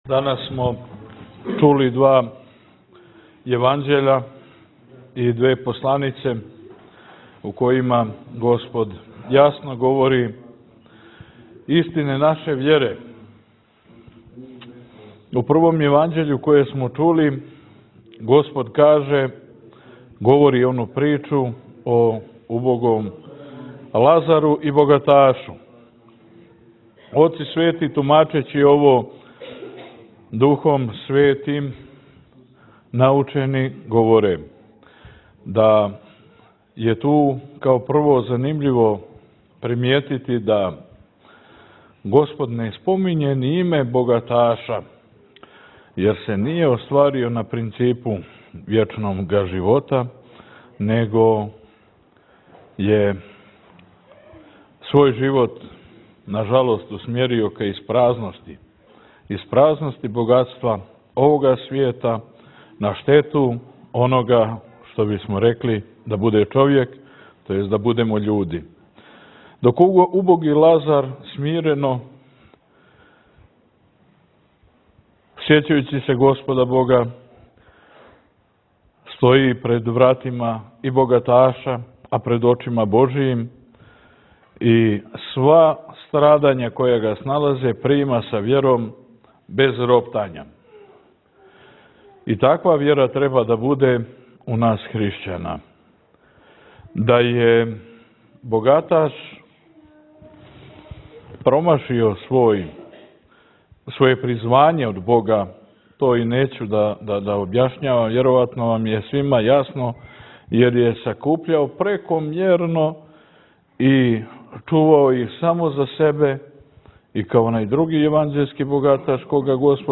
Бесједе
У 22. недјељу по Духовима, 17. новембра 2019. љета Господњег, када наша Света црква молитвено прославља Св. Јоаникија Великог и Свештеномученика Никандра, служена је Света литургија у Цркви Свете Тројице у Доњем Острогу.